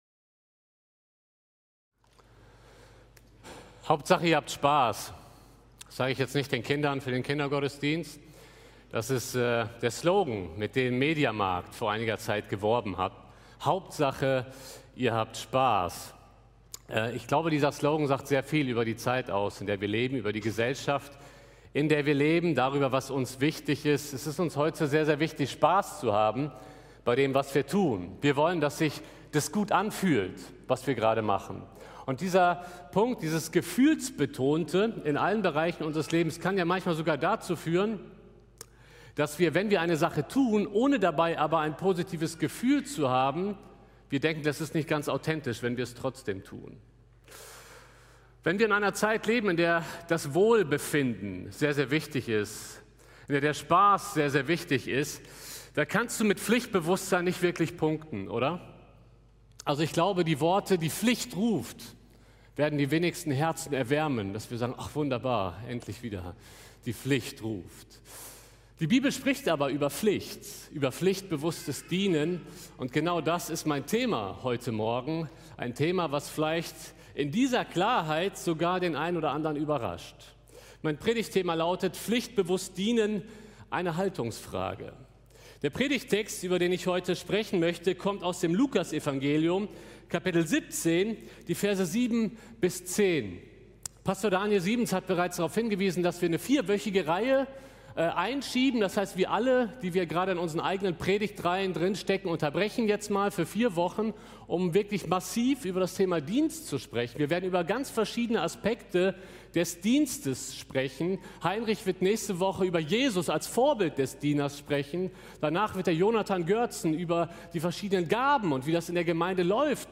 Oktober 2020 Predigt-Reihe